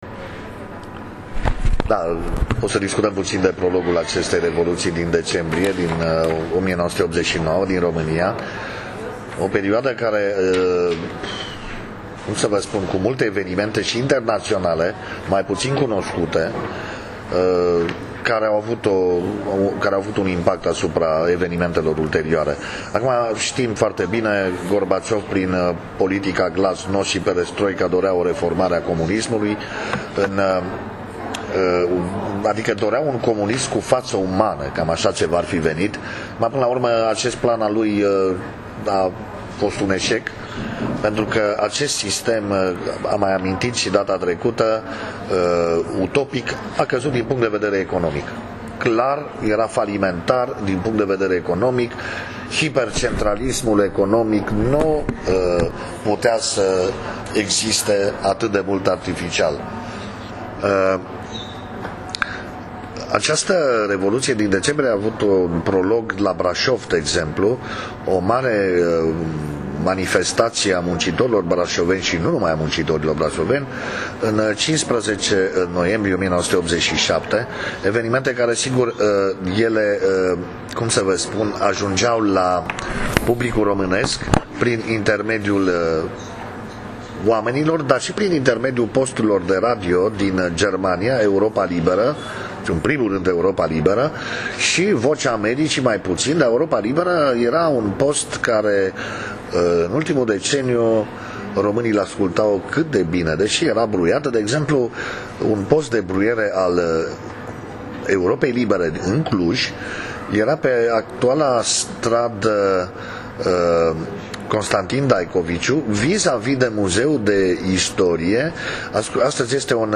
Zilele Colecțiilor 1989 - iulie 1989